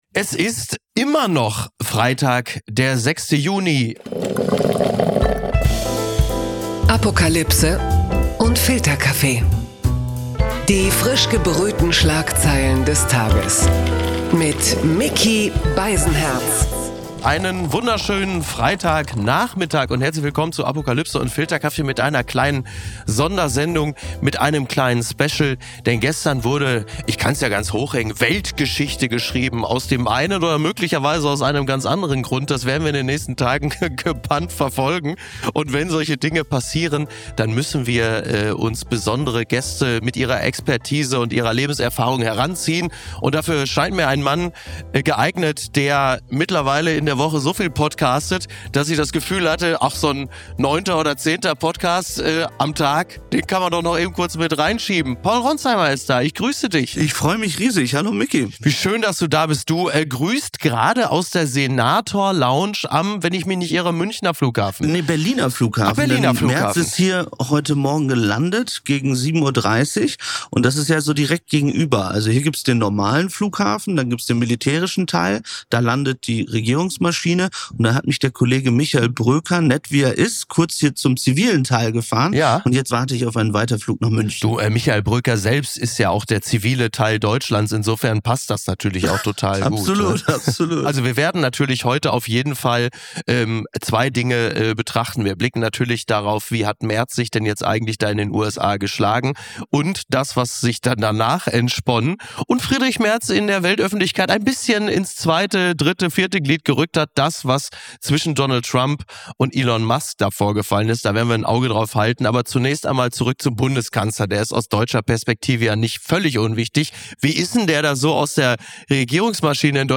Die frisch gebrühten Schlagzeilen des Tages, serviert von Micky Beisenherz. Micky wühlt sich für uns durch die wichtigsten Aufmacher, Titelthemen, Leitartikel und Tweets des Tages und serviert daraus - gemeinsam mit einem Gast - sein morgendliches News-Omelett.